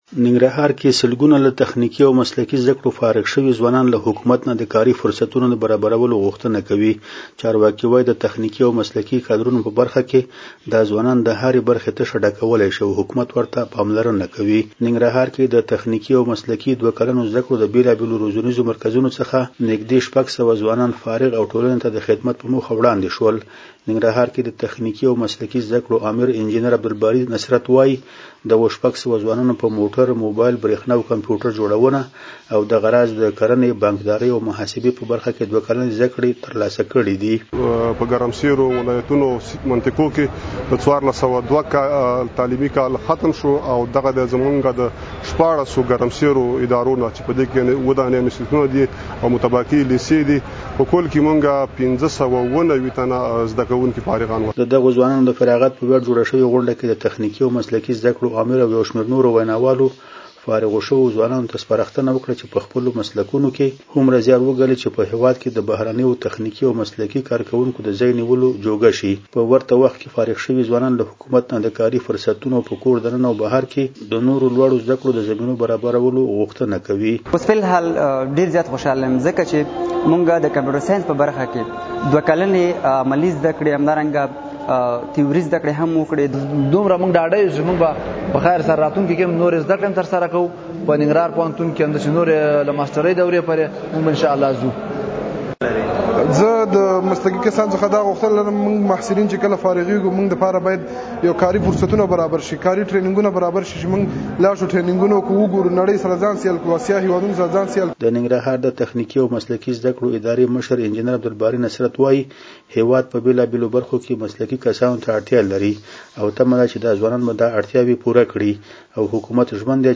له جلال آباده زمونږ خبریال رپورټ راکوي